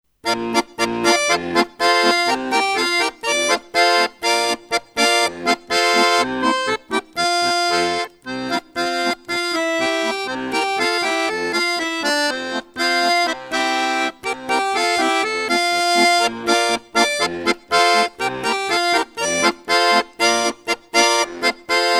Звуки аккордеона
Звучание баяна без слов